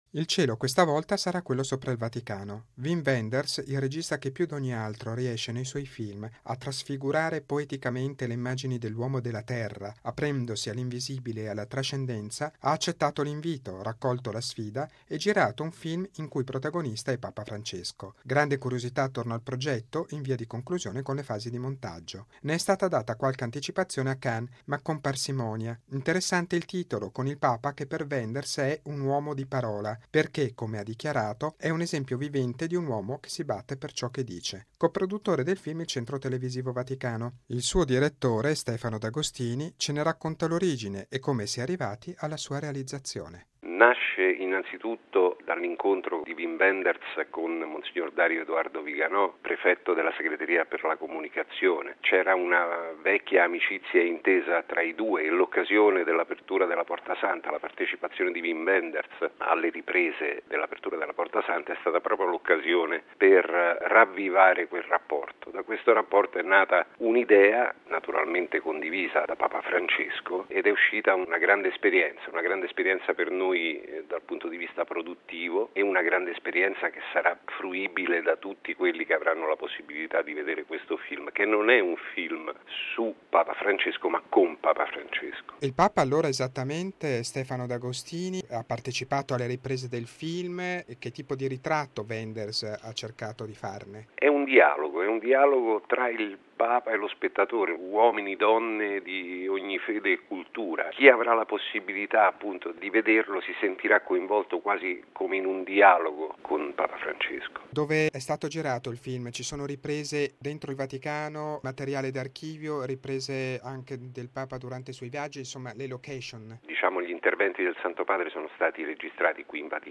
Bollettino Radiogiornale del 20/05/2017